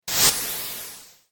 respawn.mp3